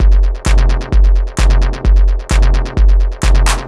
Windmill 130bpm.wav